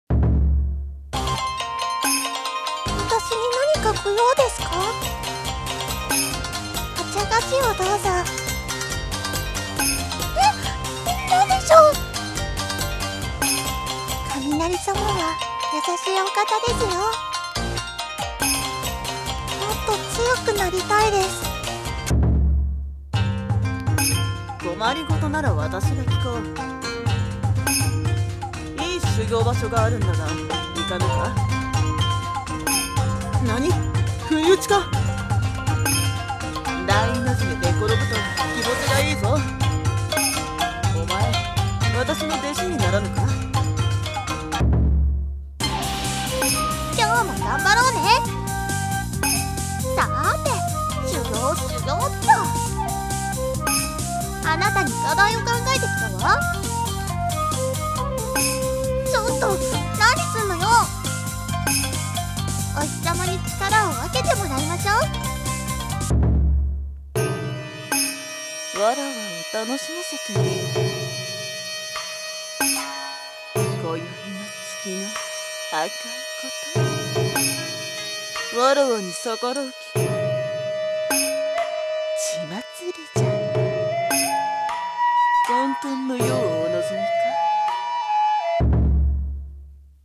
【演じ分け台本】妖怪格ゲーアプリ 女声/少年声用